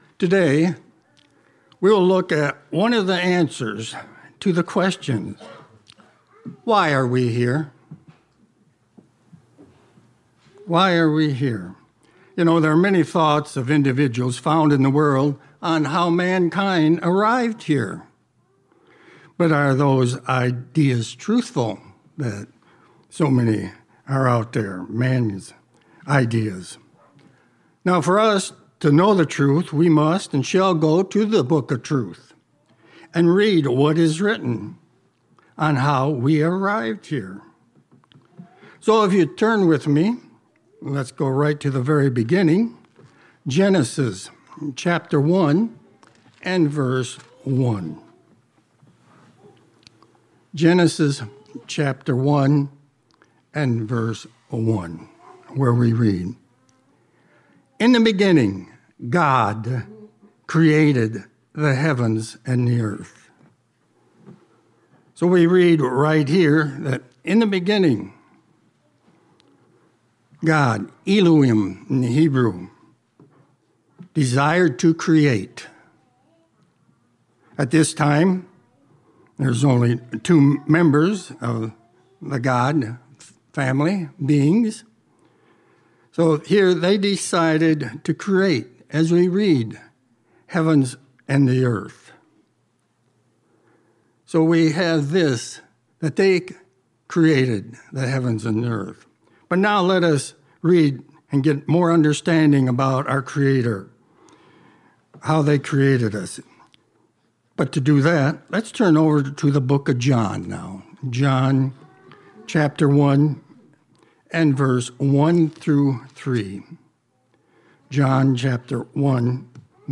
Sermons
Given in Ann Arbor, MI